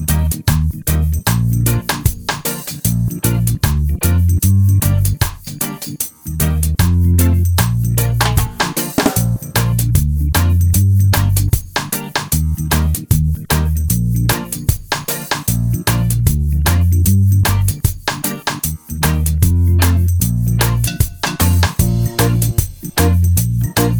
Minus Guitars Reggae 3:08 Buy £1.50